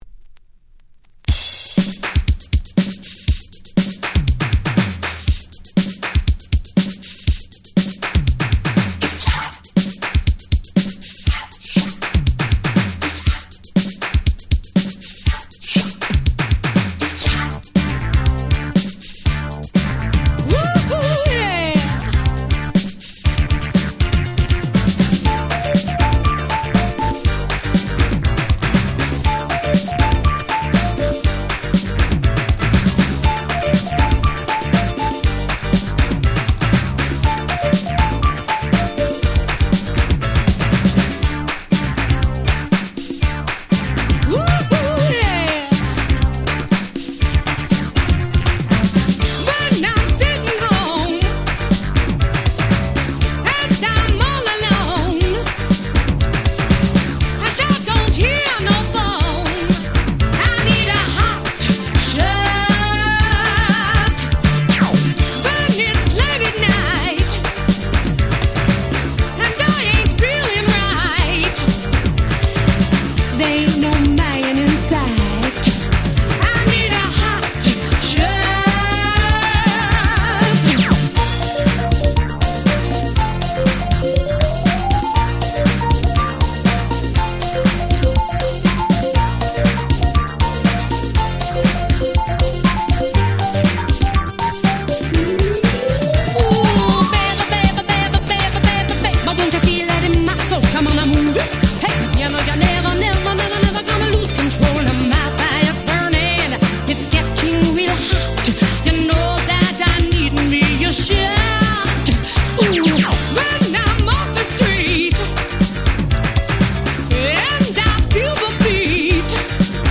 87' 後発Remix !!